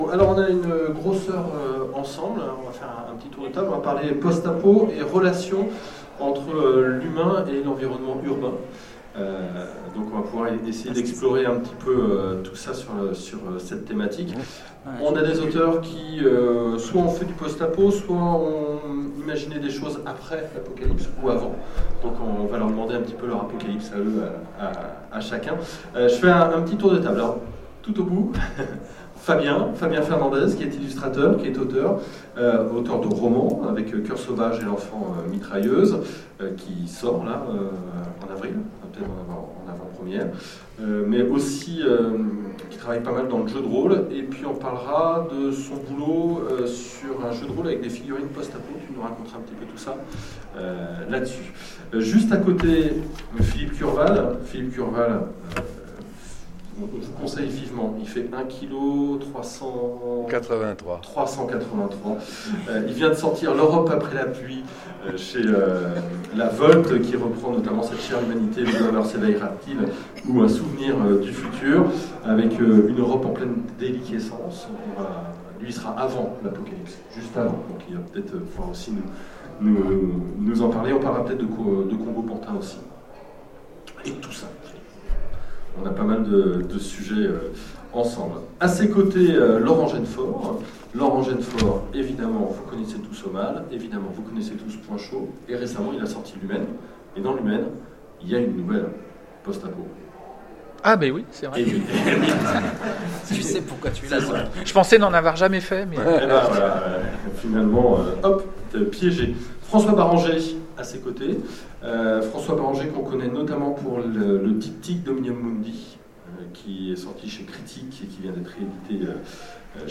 FMI 2016 : Conférence Post-Apocalypse